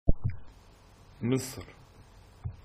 Egypt (Arabic: مصر Miṣr [mesˁr]
, Egyptian Arabic pronunciation: [mɑsˤr]), officially the Arab Republic of Egypt, is a country spanning the northeast corner of Africa and southwest corner of Asia via the Sinai Peninsula.